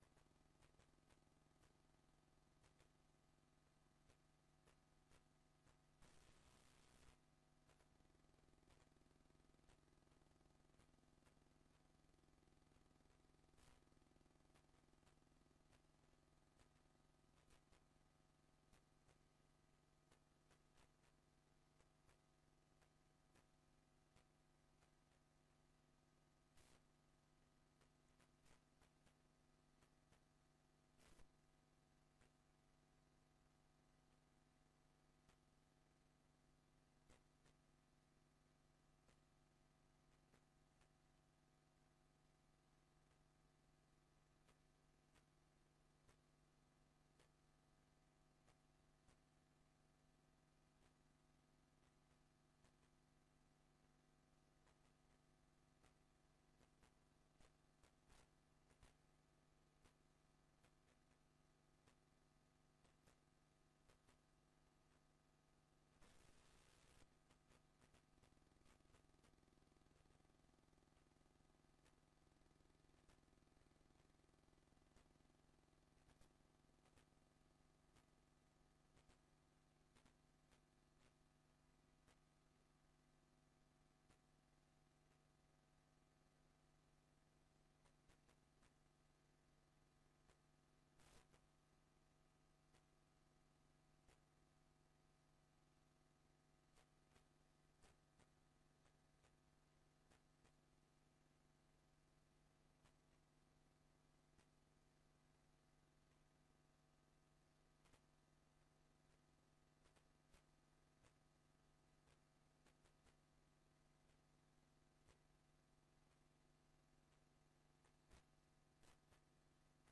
Dinsdag 10 september voorafgaand aan de commissievergadering is de eerste keer.
Locatie: Raadszaal